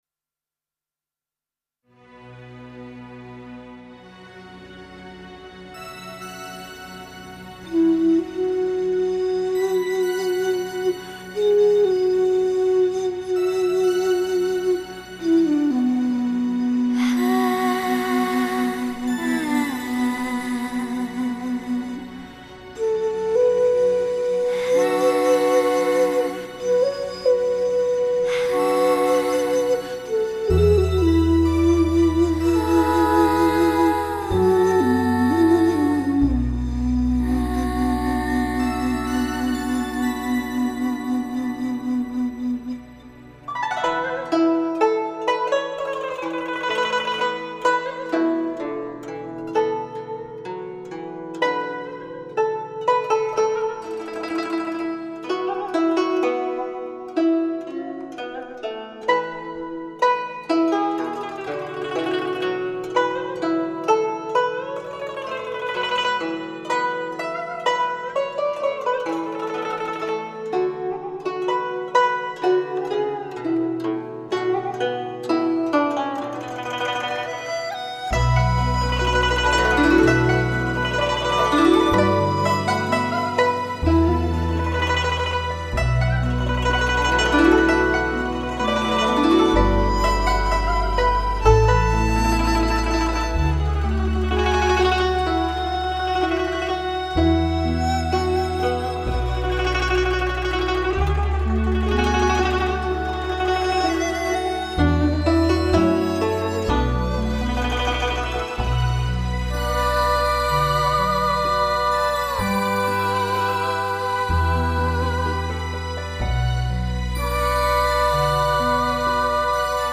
（月下情歌/人声吟唱） 激动社区，陪你一起慢慢变老！